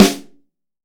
Index of /90_sSampleCDs/AKAI S6000 CD-ROM - Volume 3/Drum_Kit/ROCK_KIT2